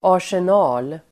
Uttal: [ar_sen'a:l]